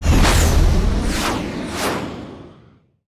engine_flyby_002.wav